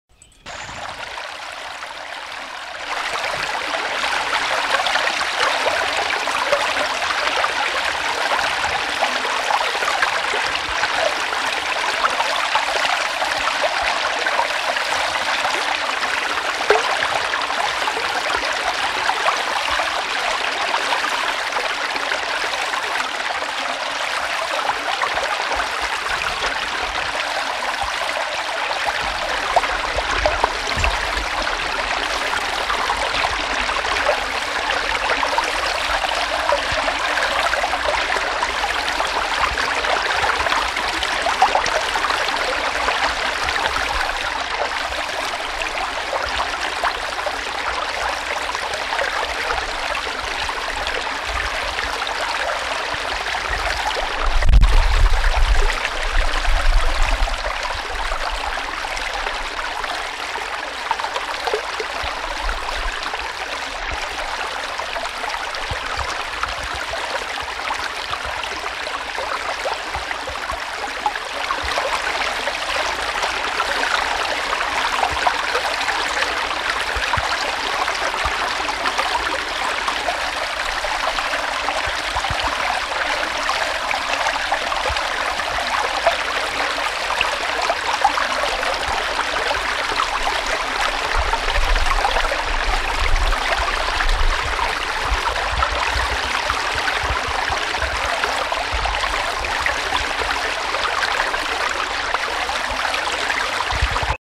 Bruits d'eau qui court
Lieu : Gers
Genre : paysage sonore